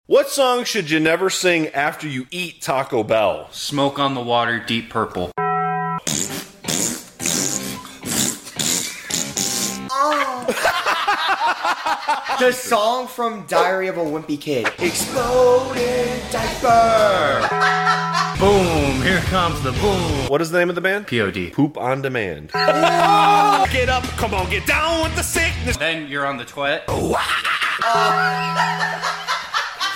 The unofficial Taco Bell aftermath sound effects free download